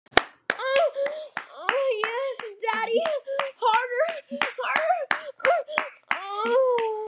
Slappping Noises Sussy Sound Button - Free Download & Play